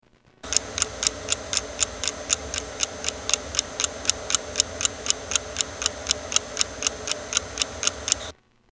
Clock tick 8 seconds